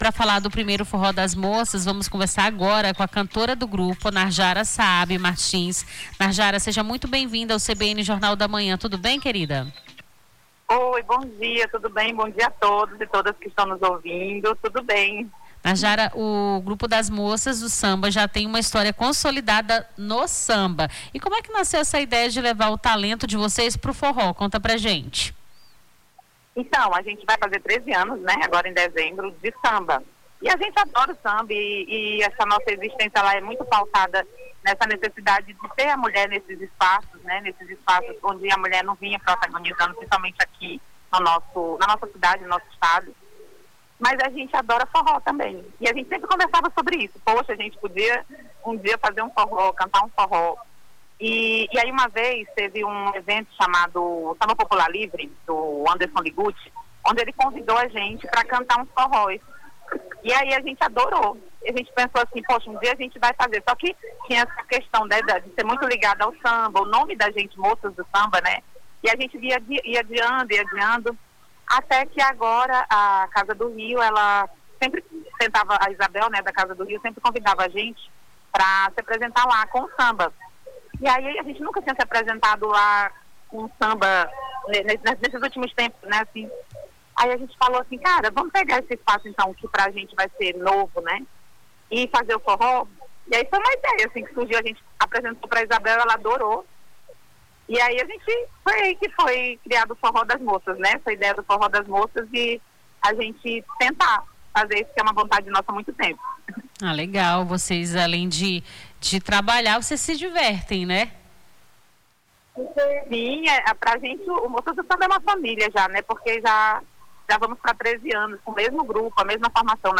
Nome do Artista - CENSURA - ENTREVISTA FORRO DAS MOCAS - 10-04-26.mp3